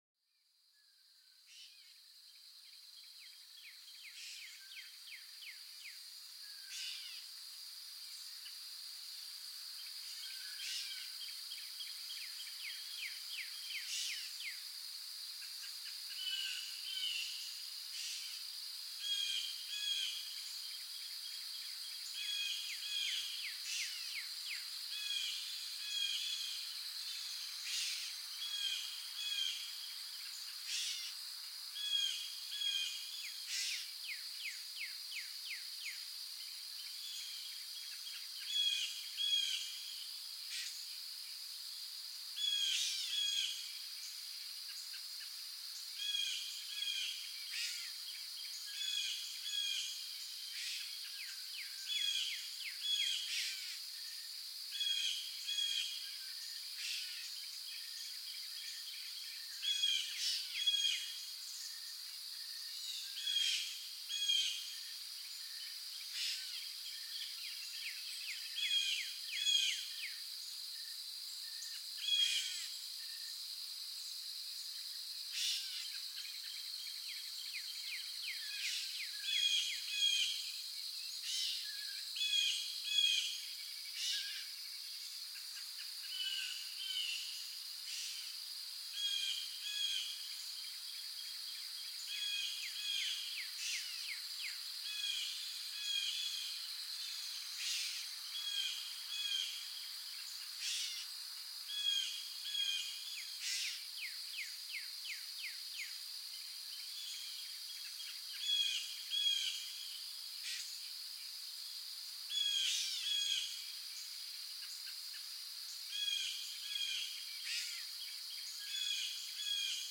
Écho Forestier: La Sérénité des Chants d'Oiseaux au Cœur de la Nature